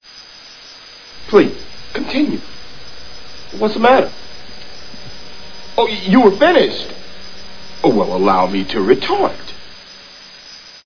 We synthetically produce a reverbed signal and aim to estimate the original form from this observation.
However because of the low quality of the original signal we have defects in the